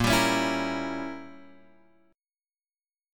A# Diminished 7th